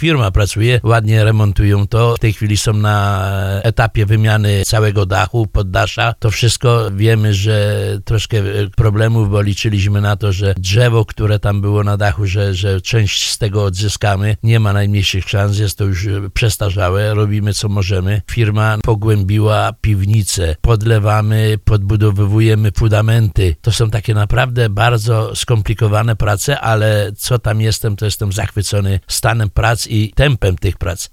Jak mówił w programie Słowo za Słowo burmistrz Tadeusz Bąk, największe problemy do tej pory wystąpiły przy wymianie stropu.